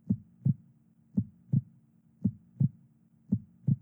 Heart Beat.wav